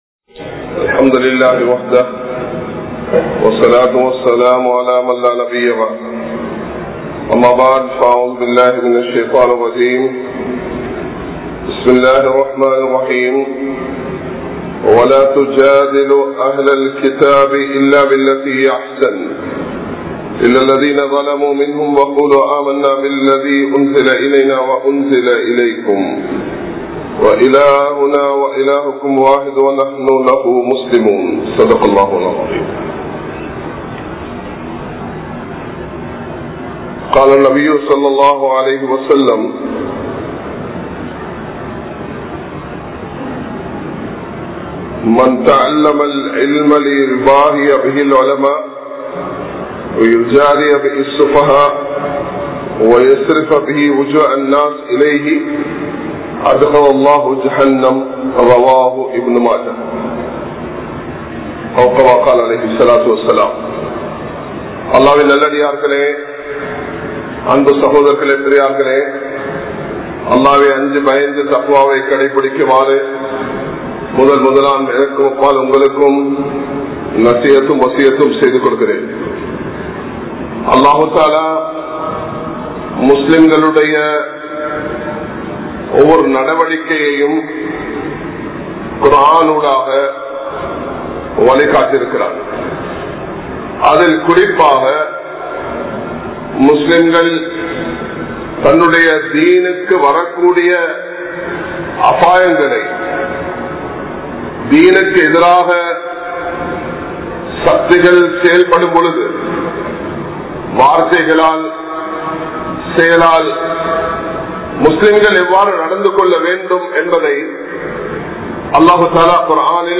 Maarkam Theriyaamal Makkalai Kulappatheerhal (மார்க்கம் தெரியாமல் மக்களை குழப்பாதீர்கள்) | Audio Bayans | All Ceylon Muslim Youth Community | Addalaichenai
Kollupitty Jumua Masjith